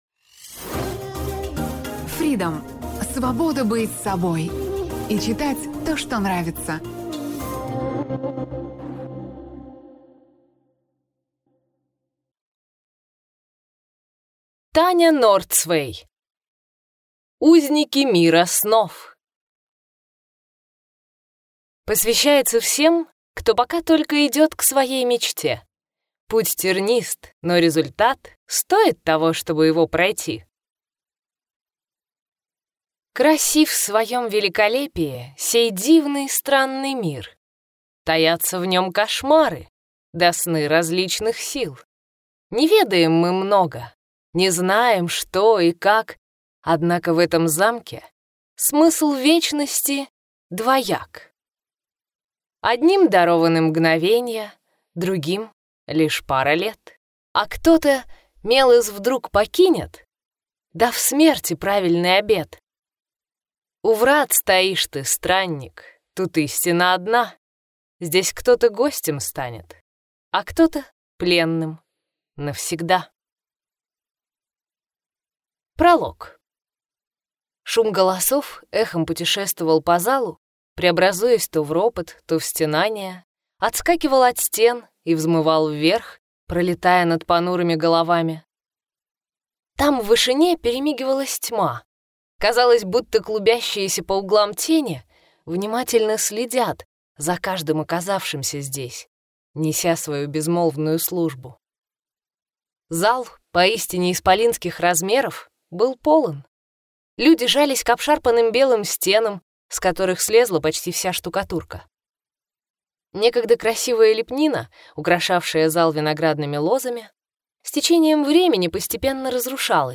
Аудиокнига Узники мира снов | Библиотека аудиокниг